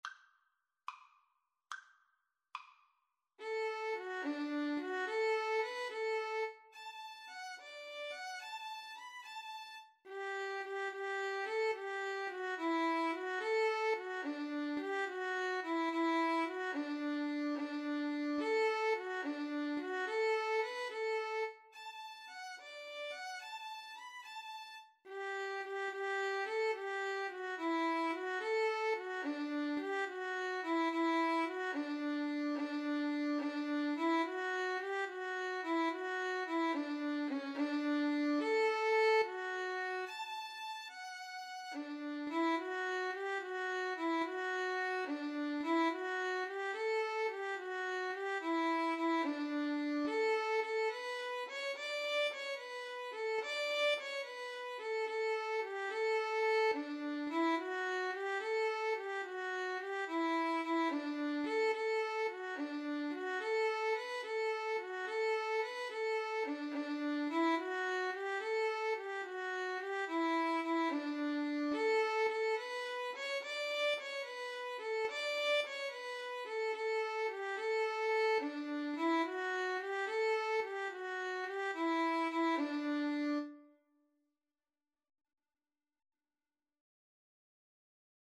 Play (or use space bar on your keyboard) Pause Music Playalong - Player 1 Accompaniment reset tempo print settings full screen
D major (Sounding Pitch) (View more D major Music for Violin-Viola Duet )
Maestoso . = c. 72